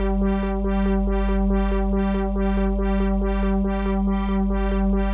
Index of /musicradar/dystopian-drone-samples/Tempo Loops/140bpm
DD_TempoDroneD_140-G.wav